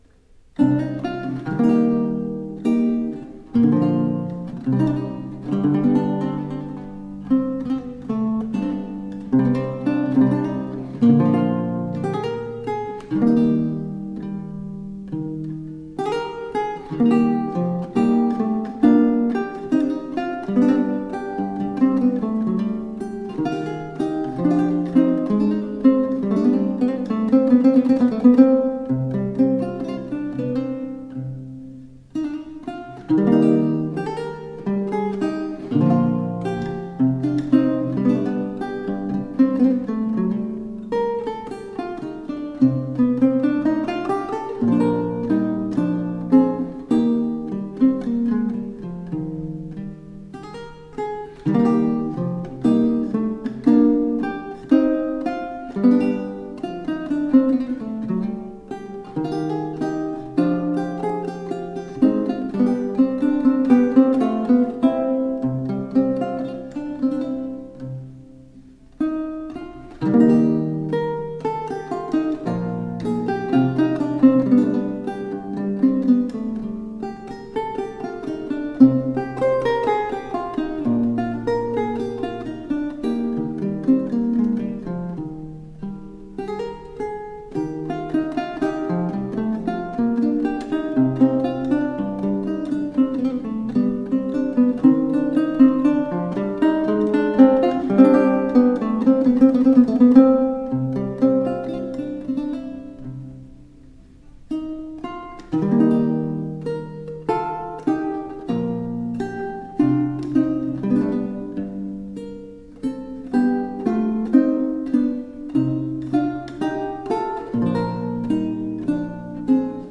Renaissance lute.
lute
Classical, Renaissance, Instrumental